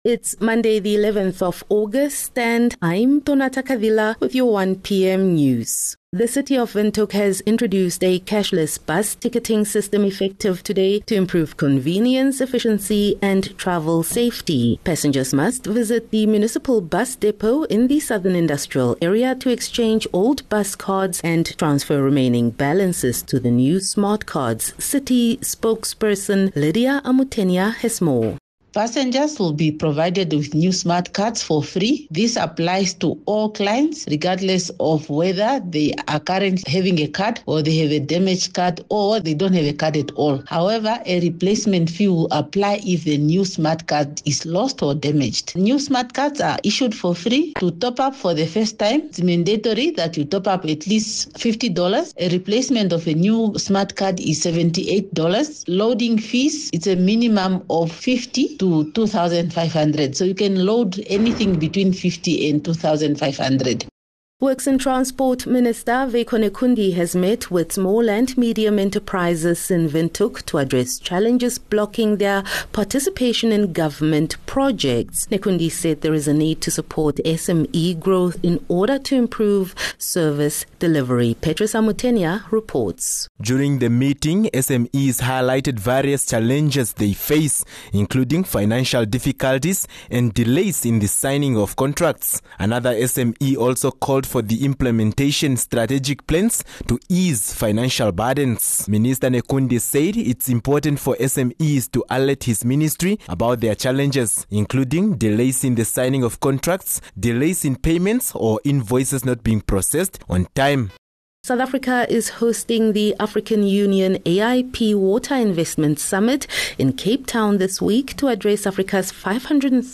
11 Aug 11 August - 1 pm news